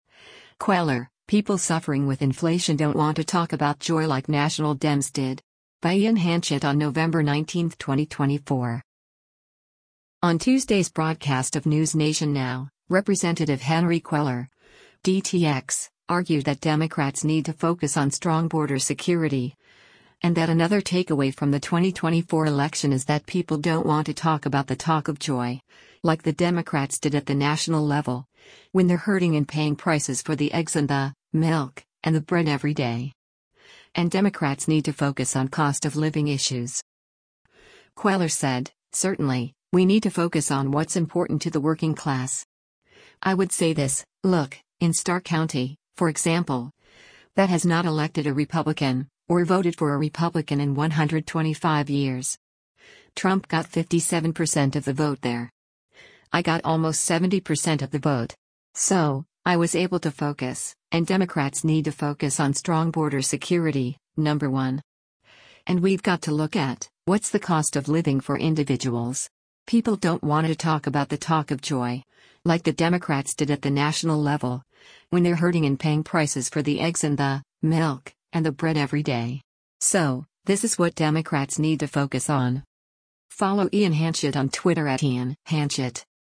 On Tuesday’s broadcast of “NewsNation Now,” Rep. Henry Cuellar (D-TX) argued that Democrats “need to focus on strong border security,” and that another takeaway from the 2024 election is that “People don’t want to talk about the talk of joy, like the Democrats did at the national level, when they’re hurting in paying prices for the eggs and the [milk] and the bread every day.”